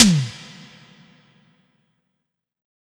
Bp Tom.wav